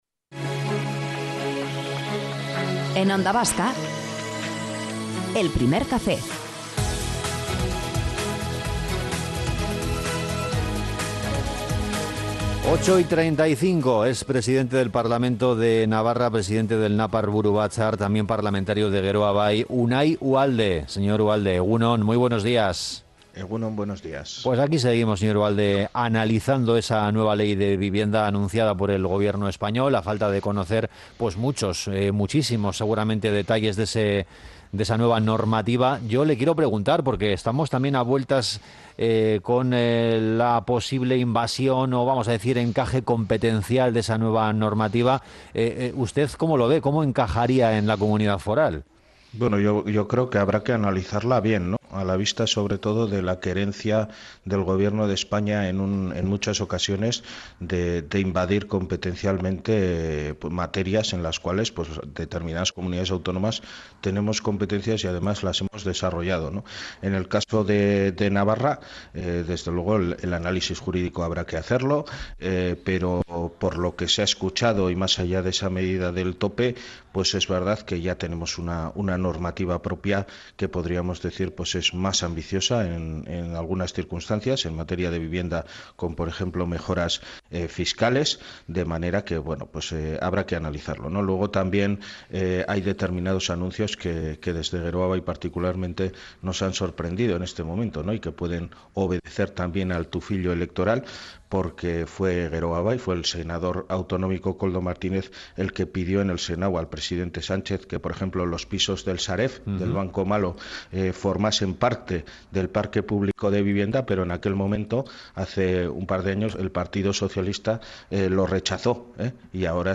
Morning show conectado a la calle y omnipresente en la red.